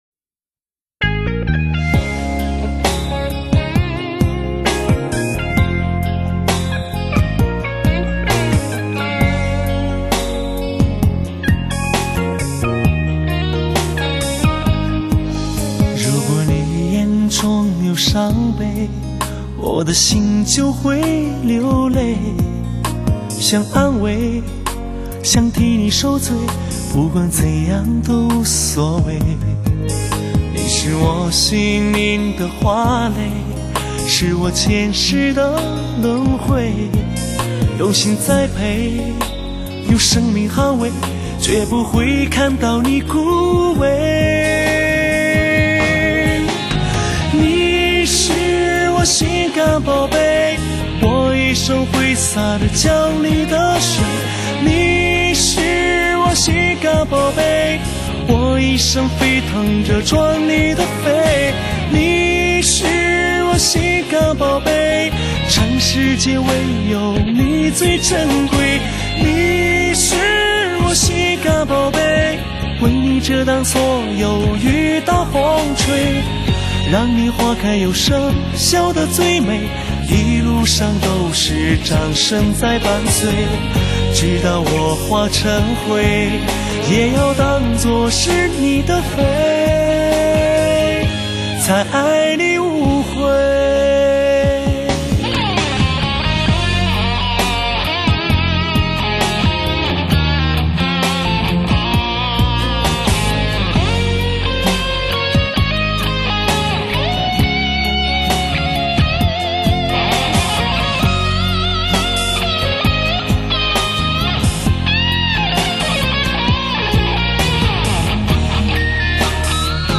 动人的旋律响在耳边，甜蜜的歌词直入心田，传达着浓浓爱意的幸福歌曲。